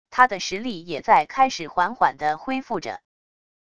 它的实力也在开始缓缓地恢复着wav音频生成系统WAV Audio Player